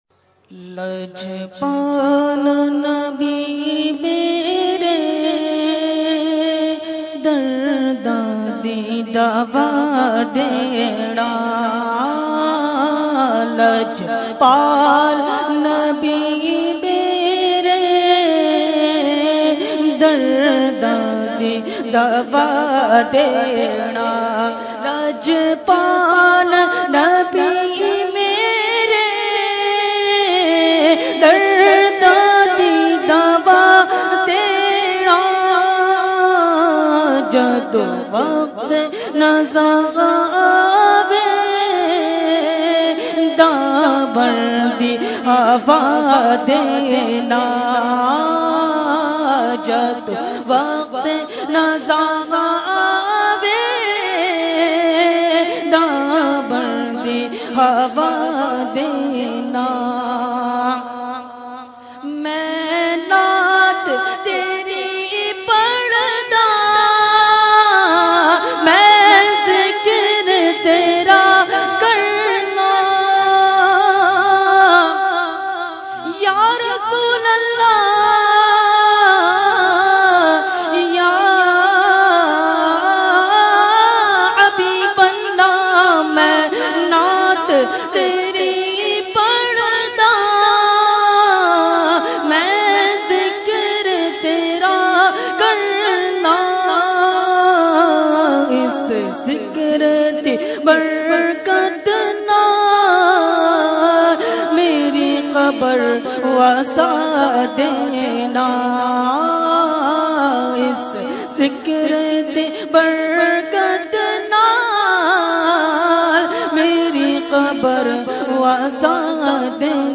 Category : Naat | Language : UrduEvent : Urs e Makhdoom e Samnani 2015